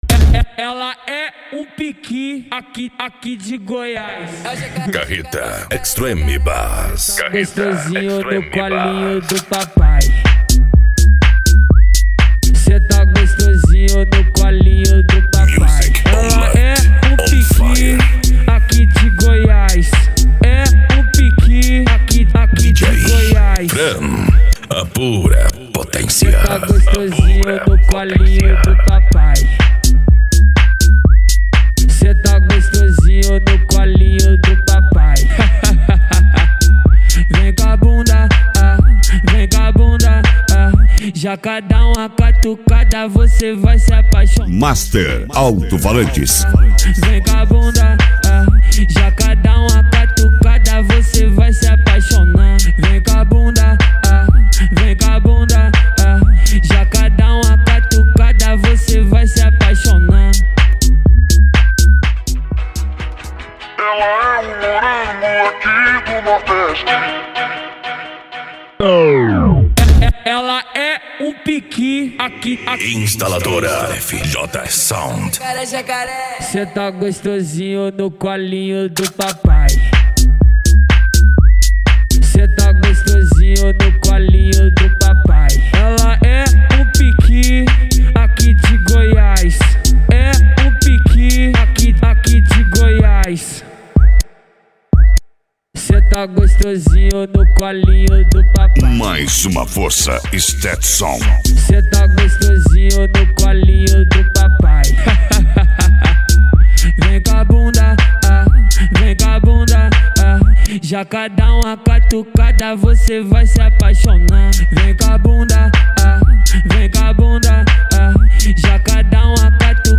Bass
Deep House
Electro House
Eletronica